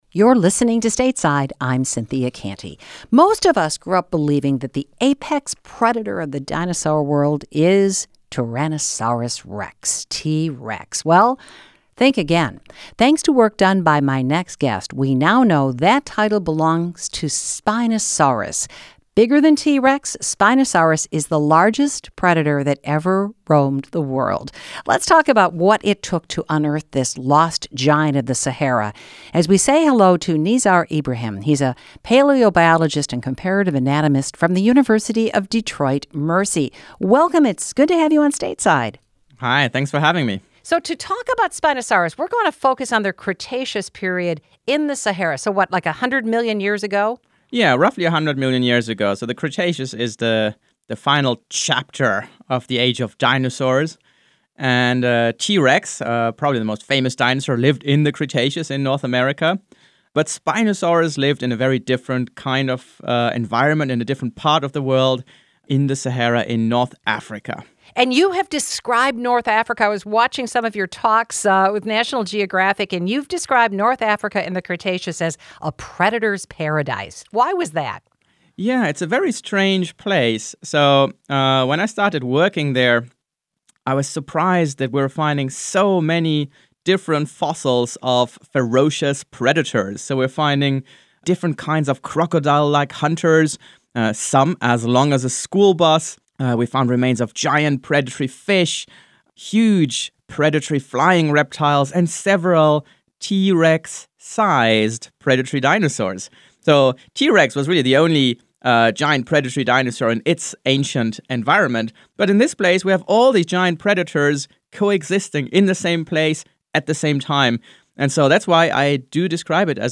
Today on Stateside, Governor Whitmer last week ordered state agencies to stop working on a proposed tunnel intended to house replacement pipelines for Enbridge's Line 5. We hear about the legal opinion from Dana Nessel that prompted that order, and how Republican lawmakers are reacting to the news. Plus, a conversation with the paleontologist who worked to unearth Spinosaurus, the largest predatory dinosaur ever discovered.